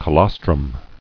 [co·los·trum]